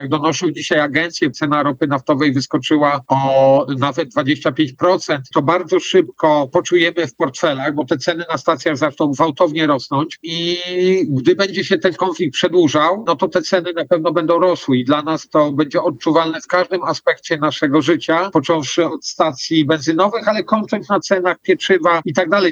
Opublikowano w Aktualności, Audycje, Poranna Rozmowa Radia Centrum